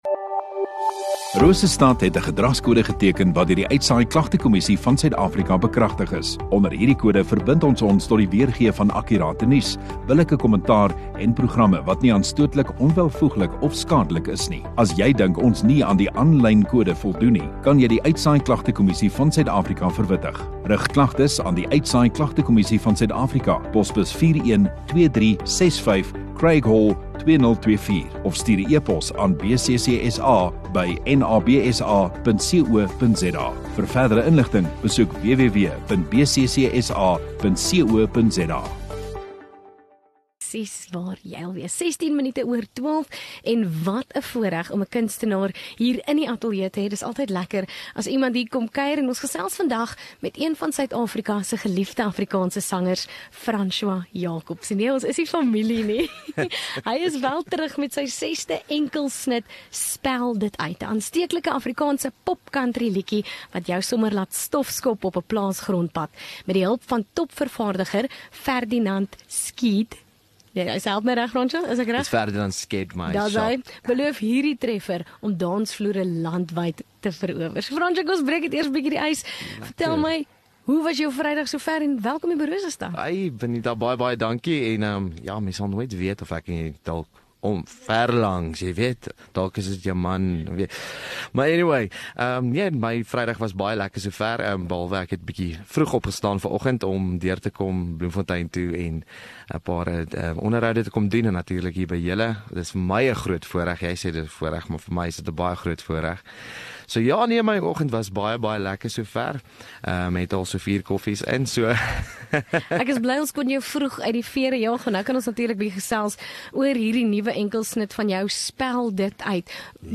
Radio Rosestad View Promo Continue Radio Rosestad Install Kunstenaar Onderhoude 8 Aug Kunstenaar